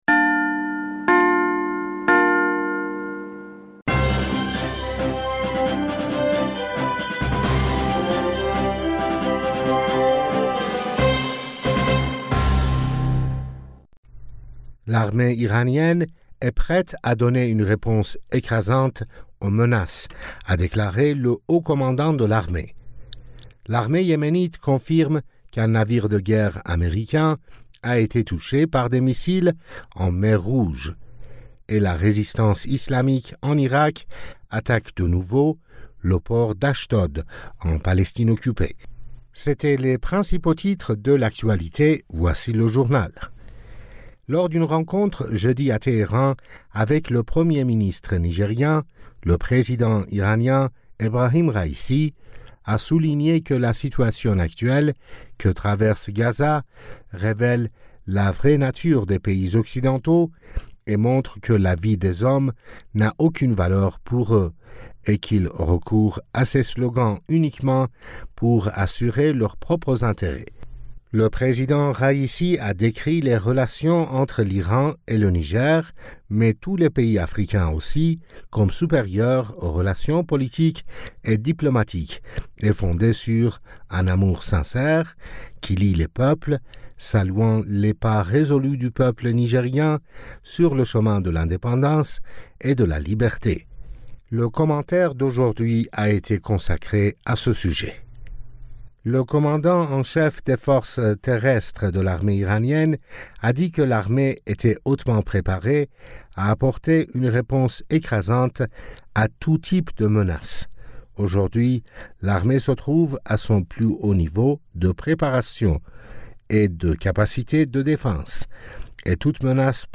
Bulletin d'information du 25 Janvier 2024